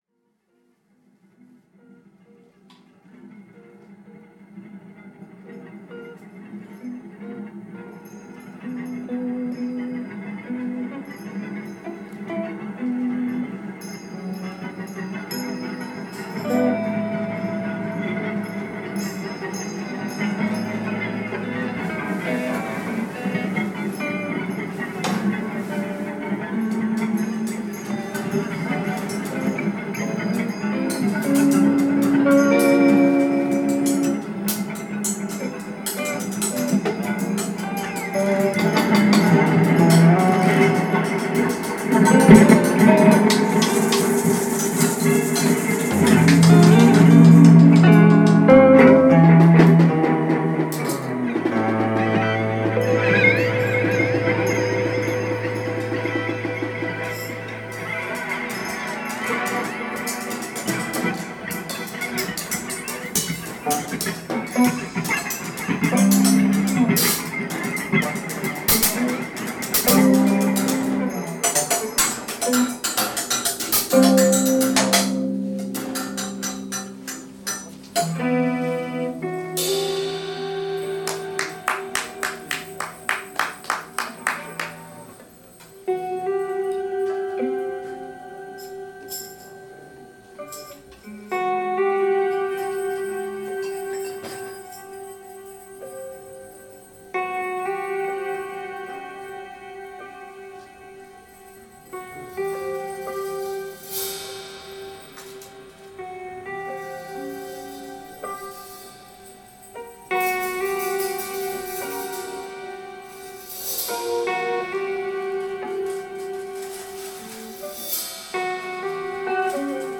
Saxophone/Bassklarinette/Percussion
E-Gitarren
Schlagzeug Live aufgenommen bei der Soester Jazznacht 07.02.2026